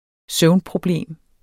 Udtale [ ˈsœwn- ]